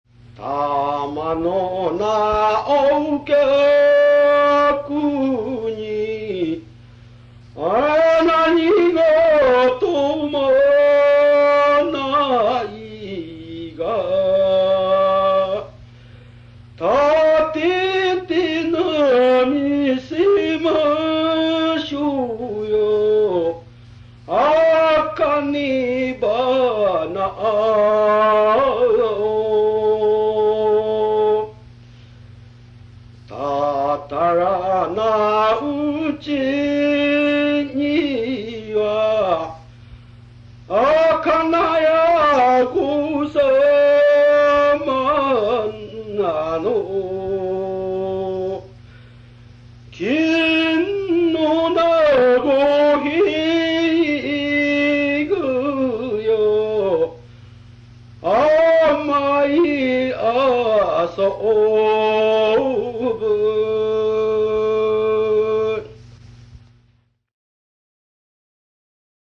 たまのお客に何事ないが（フイゴ歌・仁多郡奥出雲町大呂）
歌い手　男性・昭和39年（1964）当時54歳